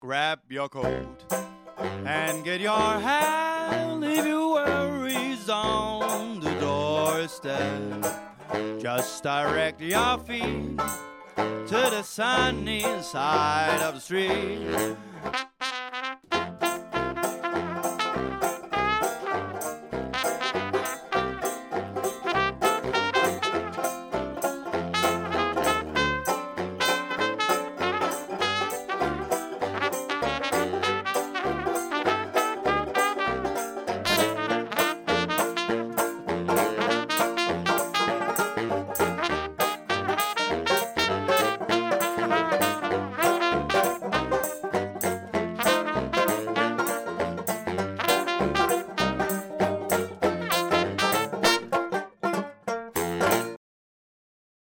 Trio
Hyggelig musik til receptioner og andre festlige lejligheder